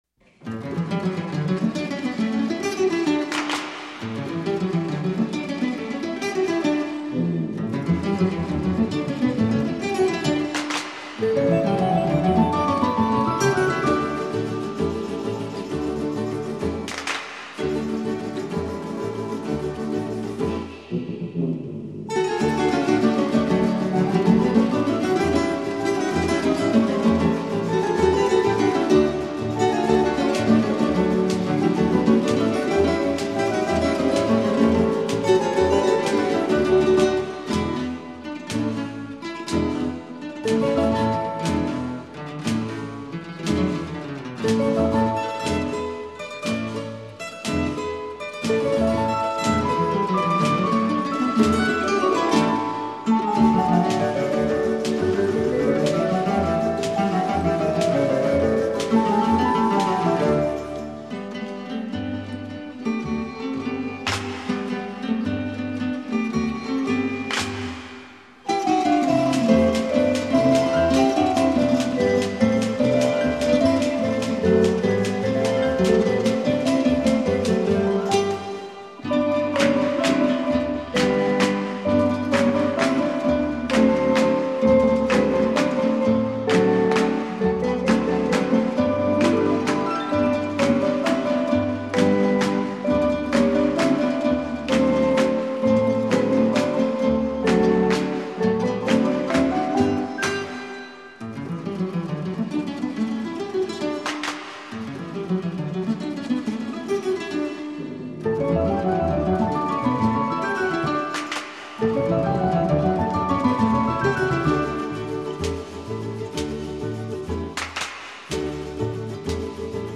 classical-guitar--mexican-hat-dance.mp3